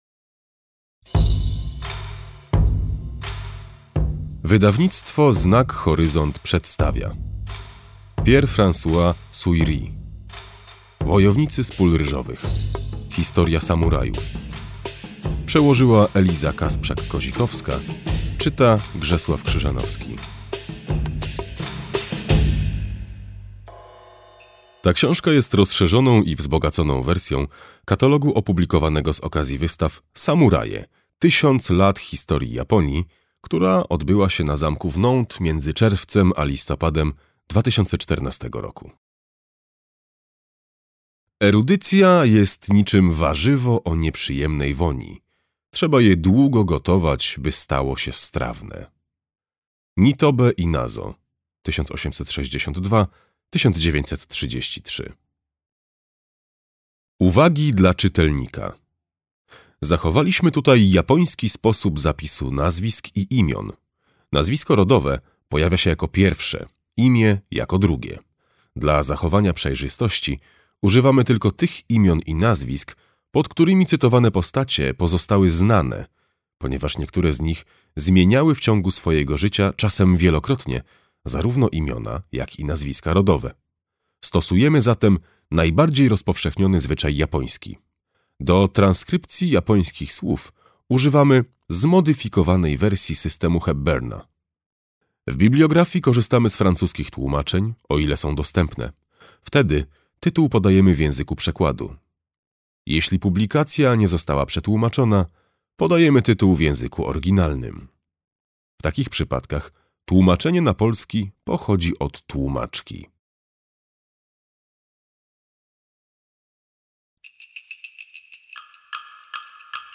Wojownicy z pól ryżowych. Historia samurajów - Pierre-Francois Souyri - audiobook + książka